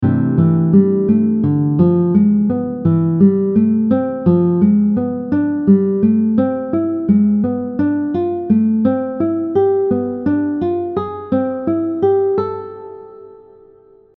Example 6 using 4 note arpeggios
For this example, we’re now alternating 4 note arpeggios from each of the two chords.
Major-6-diminished-scale-example-6.mp3